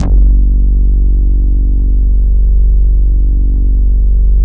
112 BASS  -L.wav